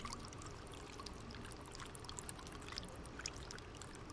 描述：潺潺流水的声音。
Tag: 流水声